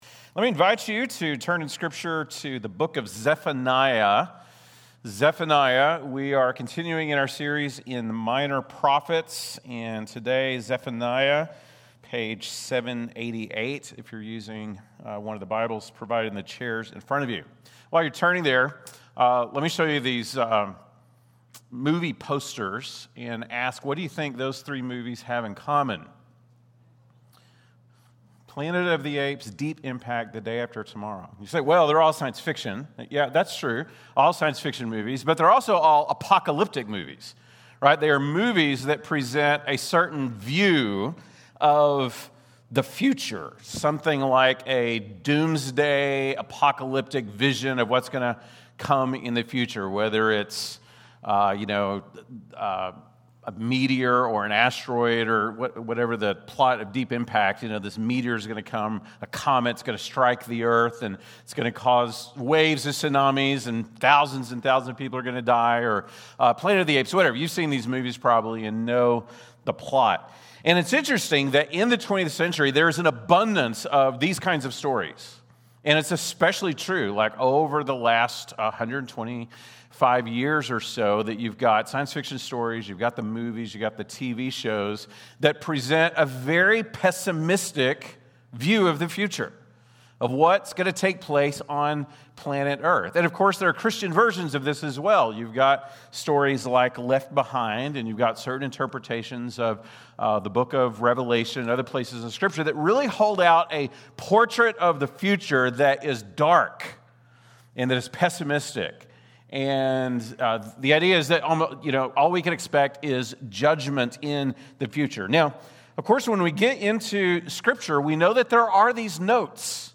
August 3, 2025 (Sunday Morning)